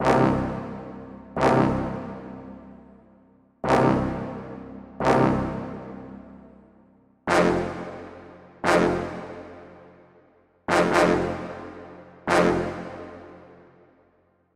标签： 起床 叫醒 语音
声道立体声